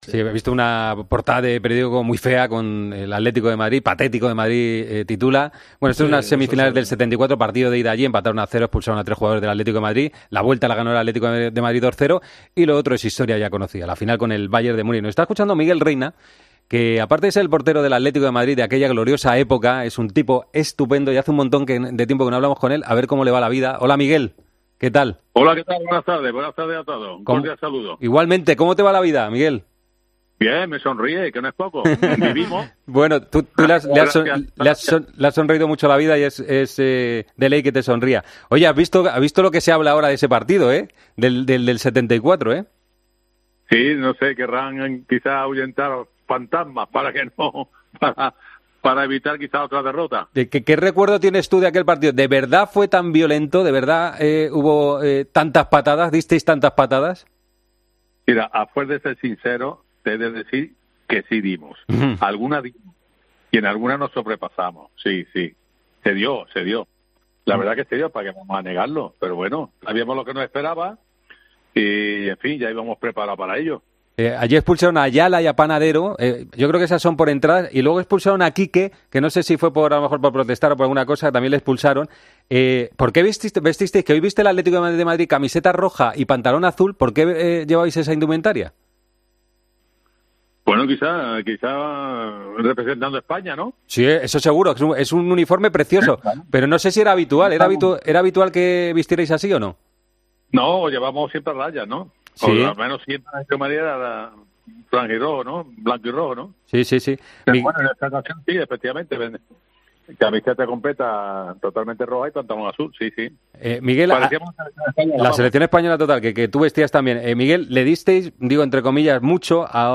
Miguel Reina, en Deportes COPE: "Si soy sincero, sí dimos muchas patadas y en alguna nos pasamos"
La batalla de Glasgow acabó 0-0 en parte gracias a las paradas del portero que este miércoles ha pasado por los micrófonos de Deportes COPE para hablar del partido y de lo que sucedió aquel día.